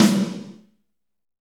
Index of /90_sSampleCDs/Northstar - Drumscapes Roland/DRM_Fast Rock/SNR_F_R Snares x